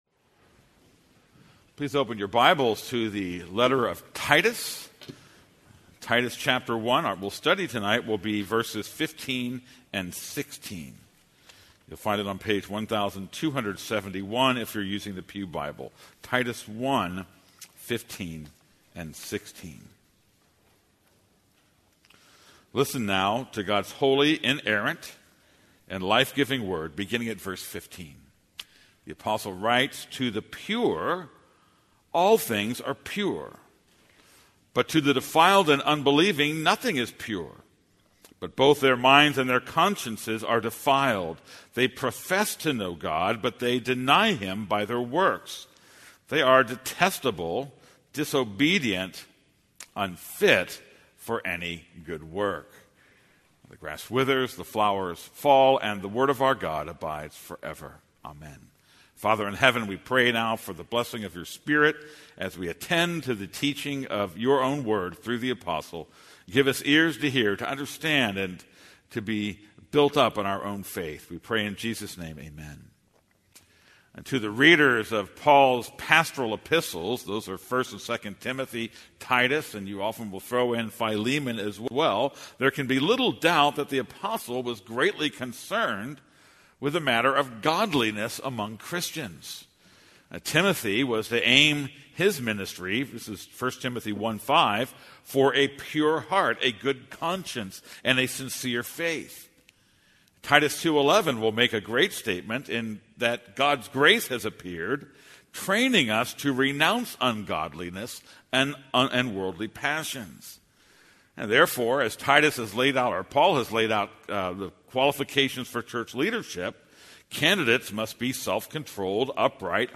This is a sermon on Titus 1:15-16.